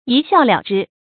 一笑了之 注音： ㄧ ㄒㄧㄠˋ ㄌㄧㄠˇ ㄓㄧ 讀音讀法： 意思解釋： 笑一笑就算了事。